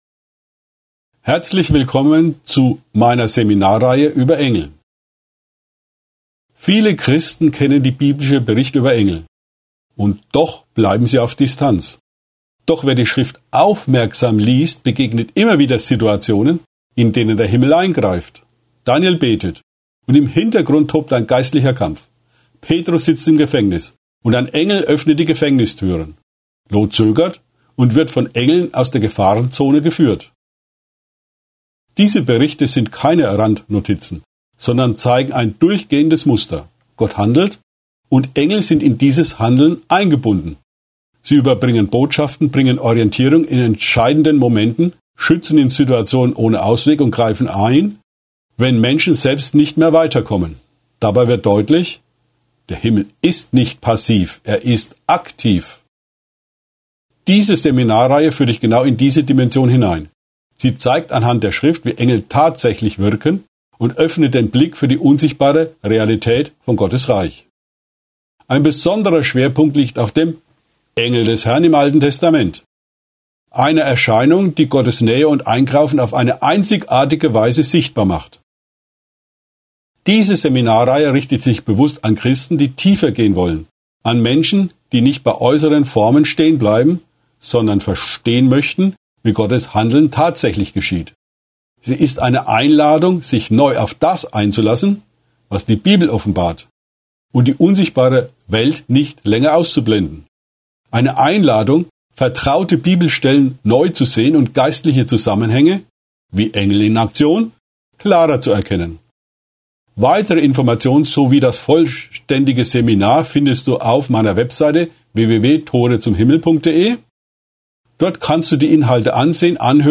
Seminarreihe: Lehre über Engel